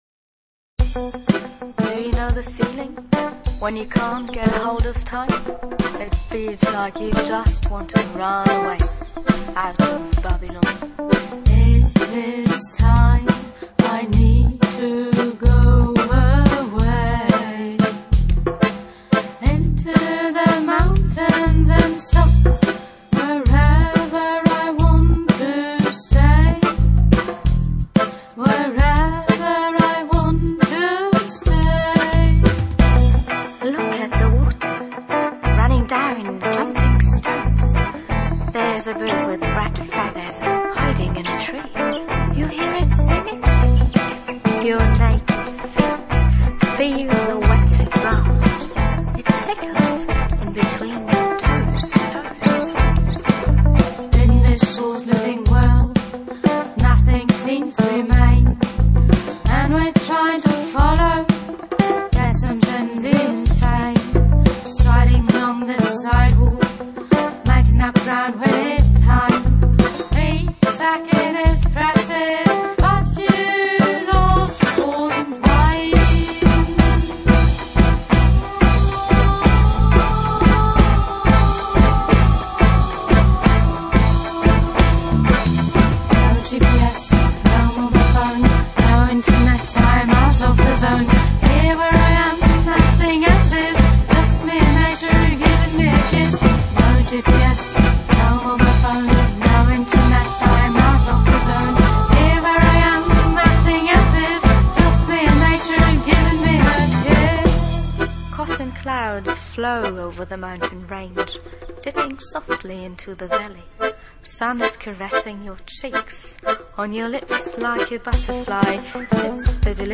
Music
world
dub, reggae, hip hop and world music from the heart